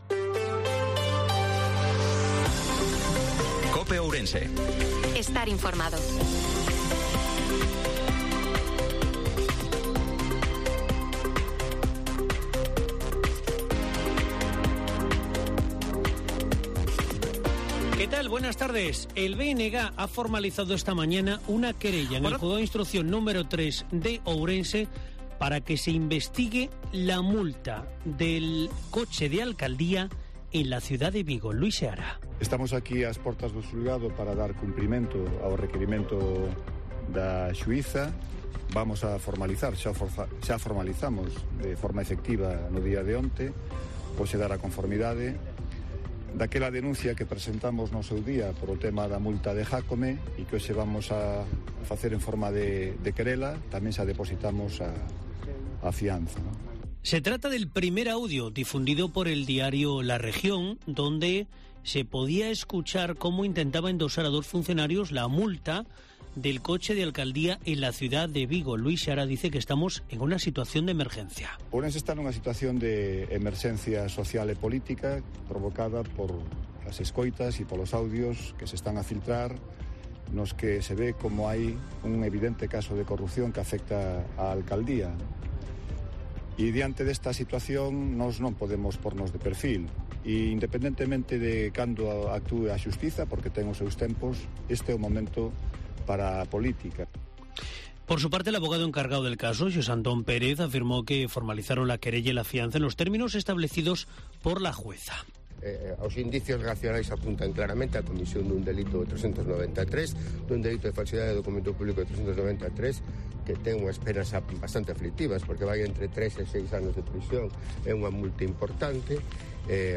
INFORMATIVO MEDIODIA COPE OURENSE-19/05/2023